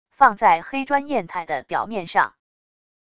放在黑砖砚台的表面上wav音频